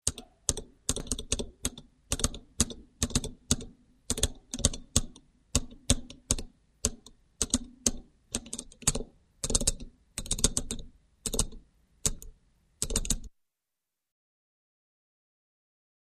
Telegraph Taps; Small Taps, Slight Hollow Thud Impacts With Clicks.